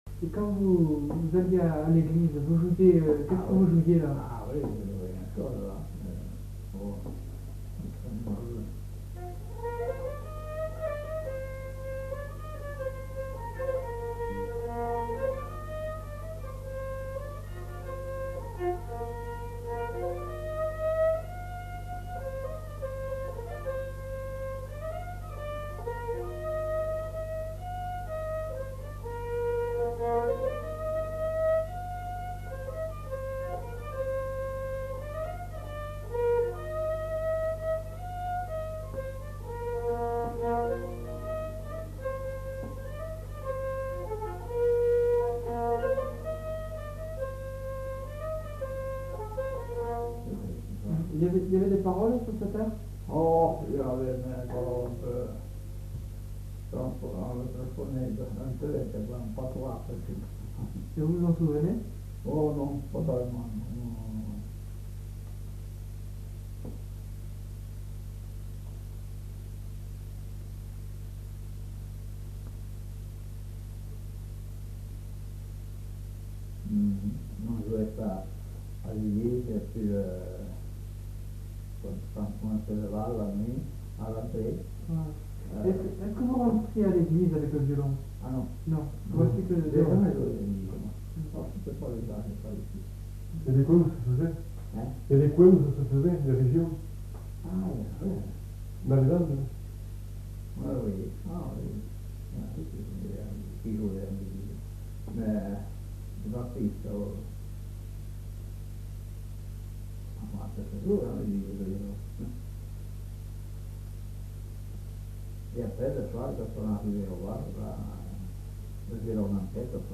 Lieu : Saint-Michel-de-Castelnau
Genre : morceau instrumental
Instrument de musique : violon